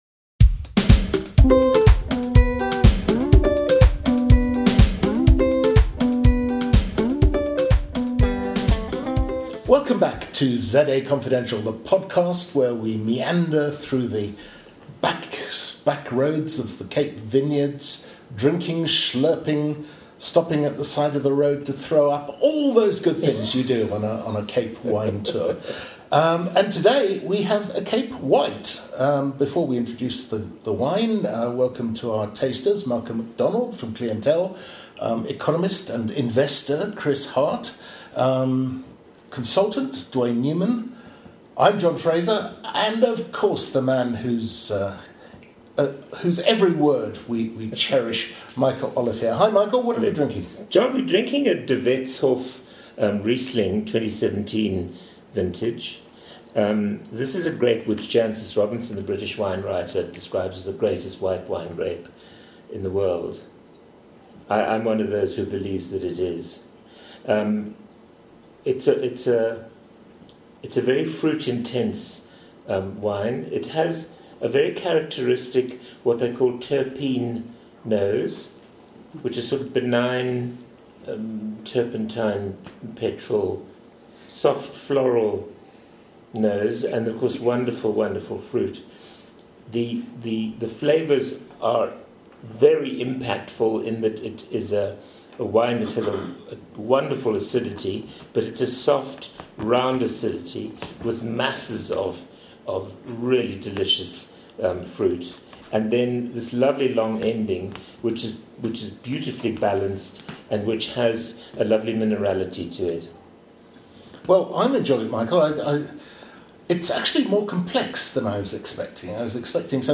No; its the ZA Confidential wine-tasting panel whooshing into action.